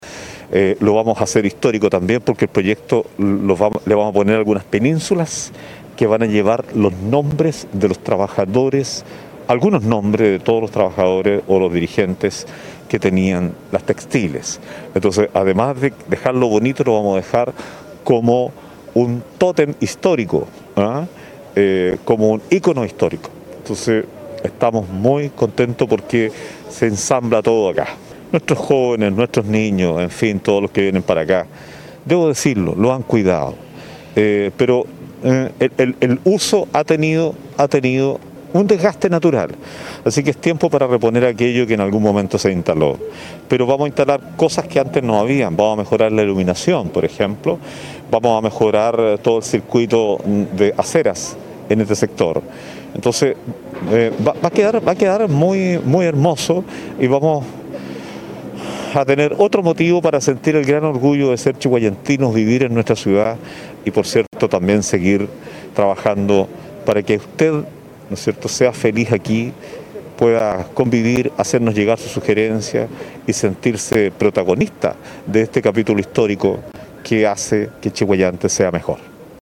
“Cada una de las penínsulas va a llevar el nombre de los dirigentes más destacados de la textil, porque aquí venían los trabajadores a comer”, recordó el jefe comunal, Antonio Rivas.